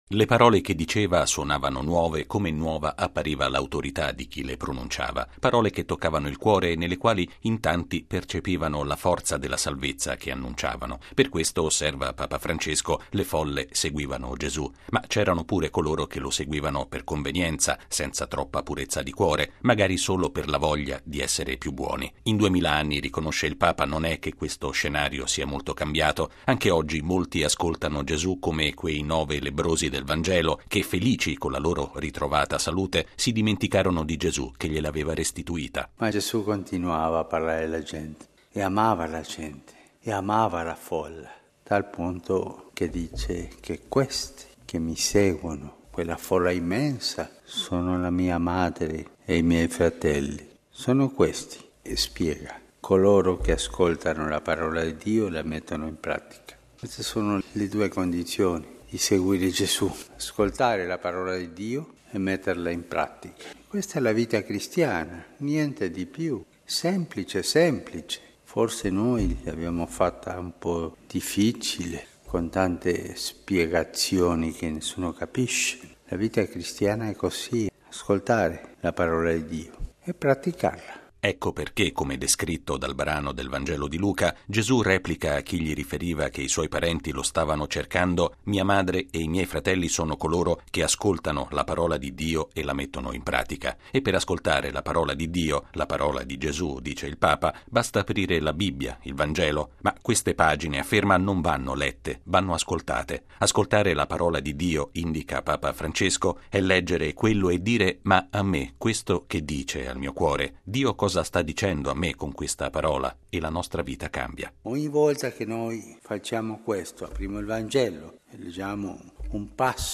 La vita cristiana è “semplice”: ascoltare la Parola di Dio e metterla in pratica, non limitandosi a “leggere” il Vangelo, ma chiedendosi in che modo le sue parole parlino alla propria vita. Lo ha ribadito Papa Francesco alla Messa del mattino celebrata nella cappella di Casa Santa Marta.